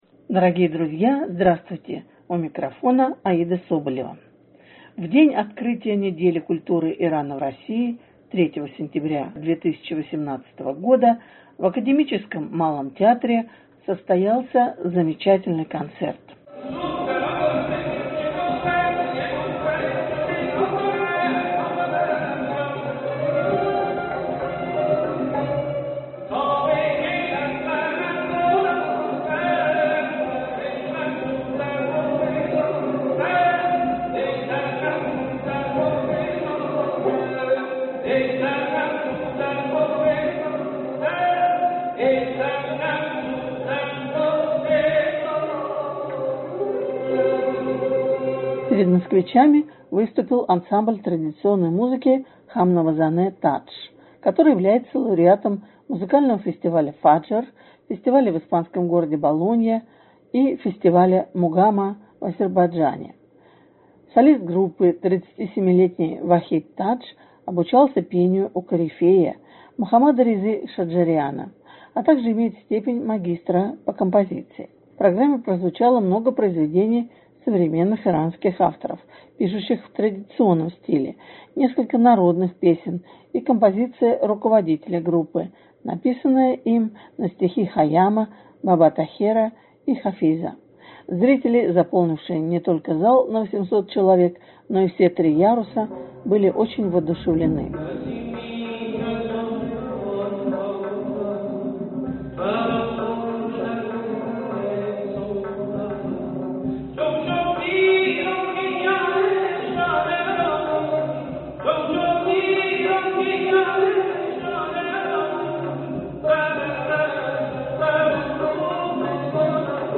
В день открытия Недели культуры Ирана в России, 3 сентября 2018 года, Академическом Малом театре состоялся замечательный концерт.
(фрагмент начала выступления) Четыре танцора под аккомпанемент большого барабана и зурны показали на сцене целое п